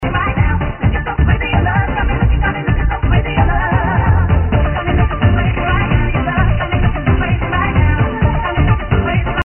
~All tracks are Remixes, unless otherwise stated~